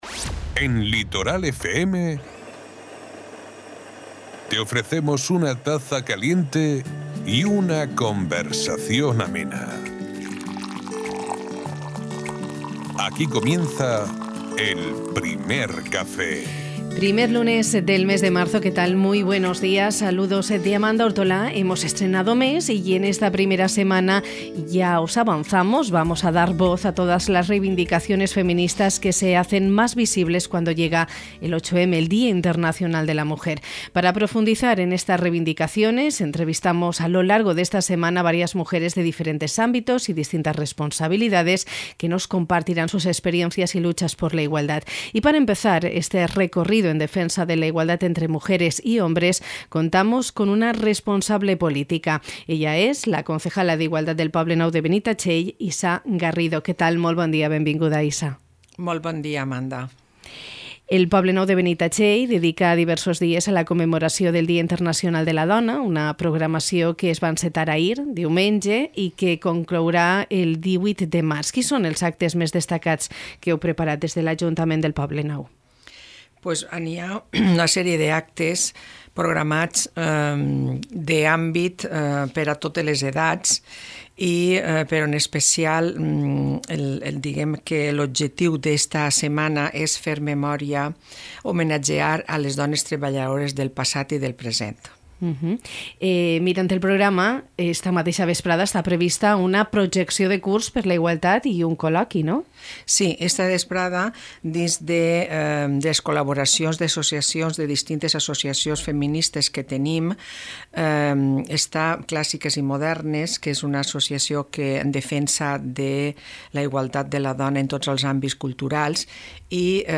Hemos estrenado mes marzo en el Primer Café poniendo el foco en el Día Internacional de la Mujer, con la intención de dedicar esta primera semana a dar voz a las reivindicaciones feministas, que se hacen más visibles cuando llega el 8M.